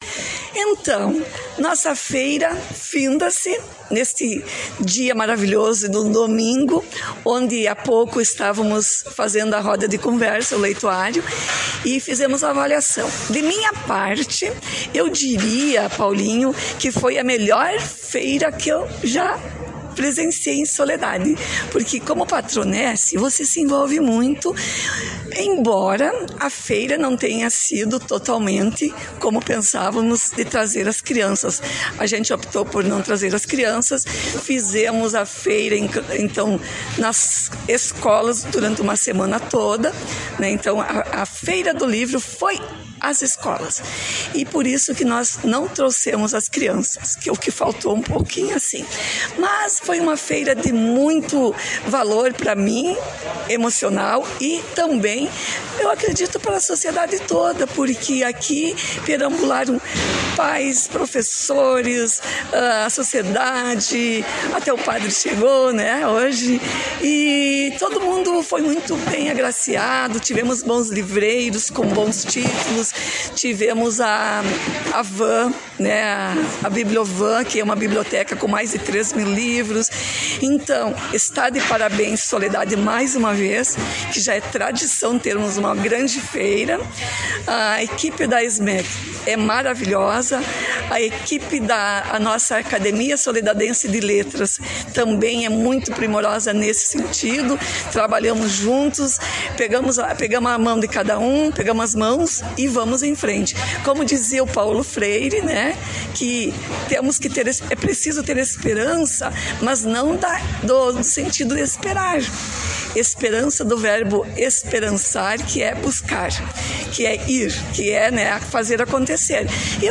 Acompanhe no player acima a notícia completa com a secretária da SMECD de Soledade, Ádria Brum de Azambuja.